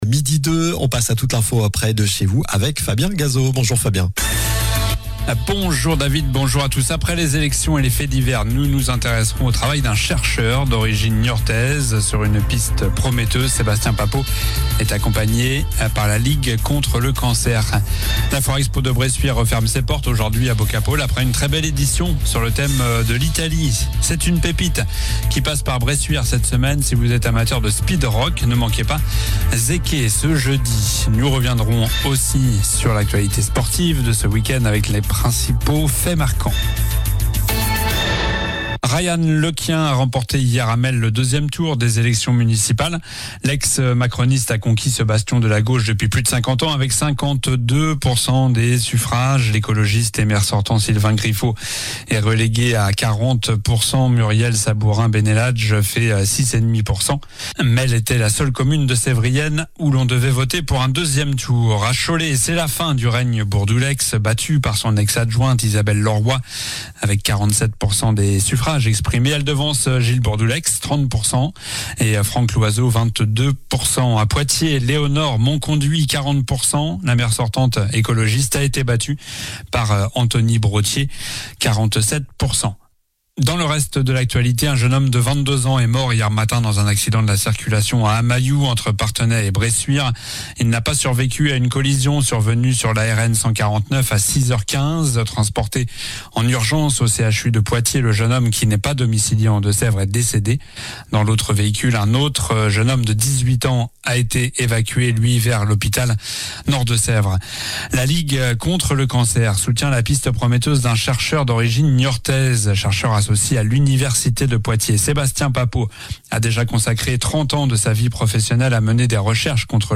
Journal du lundi 23 mars (midi)